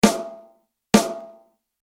Drumset-Mikrofonierung
Durch die leichte Schrägstellung der Mikrofonkapsel zum Fell trifft der Schall leicht seitlich auf die Mikrofonkapsel. Auf Grund der speziellen Richtcharakteristik wird der so einfallende Schall um einige dB gedämpft.